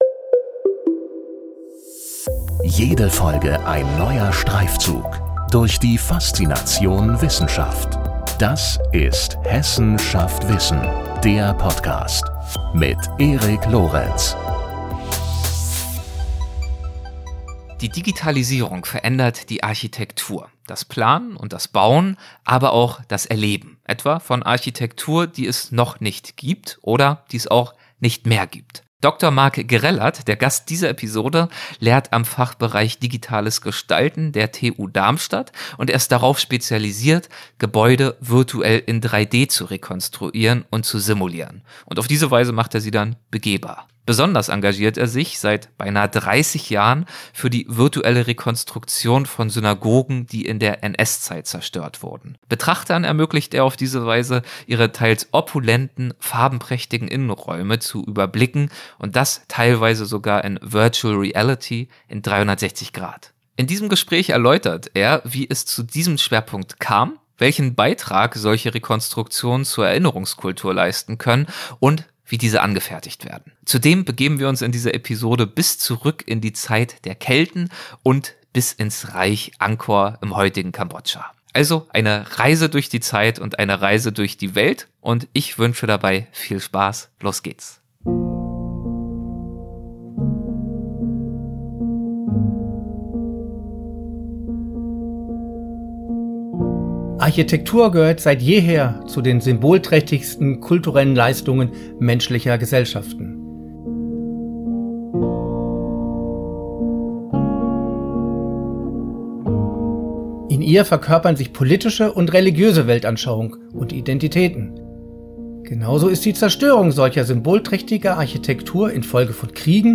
In diesem Gespräch erläutert er, wie es zu diesem Schwerpunkt kam, welchen Beitrag solche Rekonstruktionen zur Erinnerungskultur leisten können und wie diese angefertigt werden. Zudem begeben wir uns in dieser Episode bis zurück in die Zeit der Kelten … und bis ins Reich Angkor im heutigen Kambodscha.